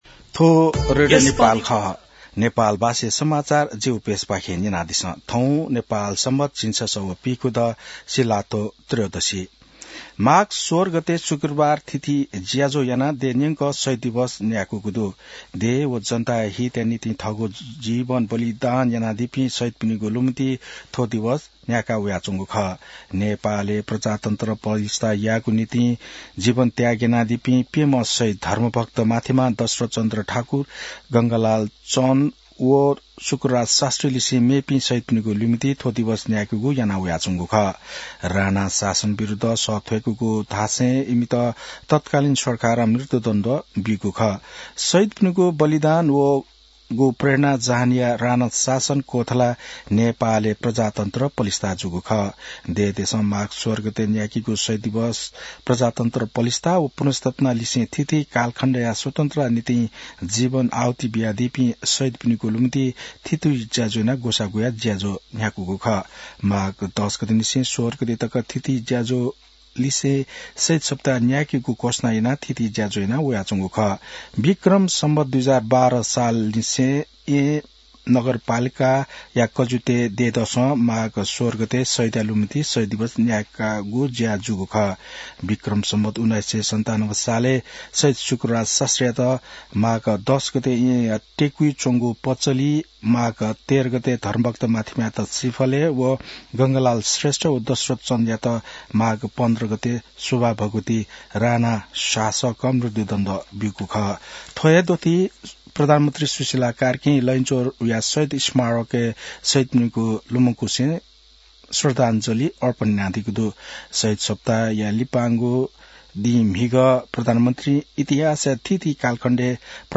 नेपाल भाषामा समाचार : १७ माघ , २०८२